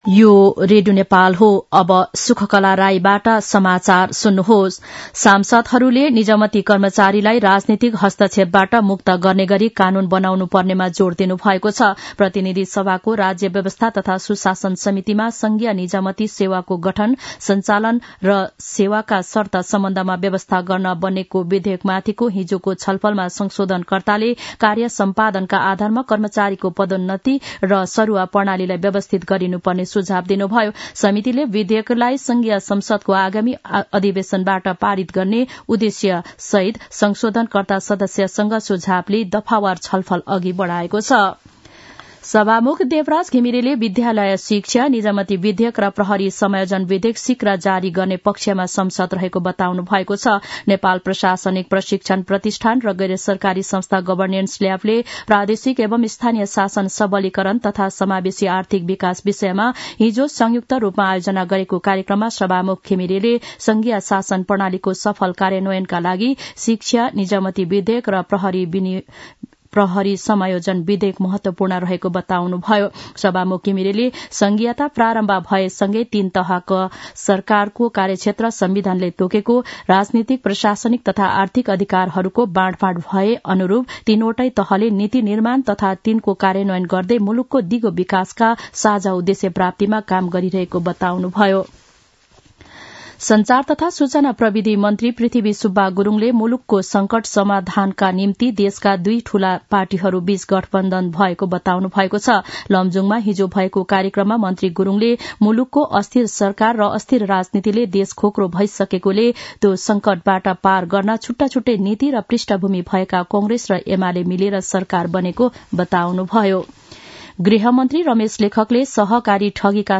मध्यान्ह १२ बजेको नेपाली समाचार : ६ पुष , २०८१
12-am-nepali-news-1-15.mp3